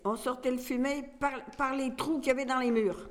Fonds Arexcpo en Vendée
Catégorie Locution